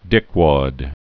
(dĭkwăd)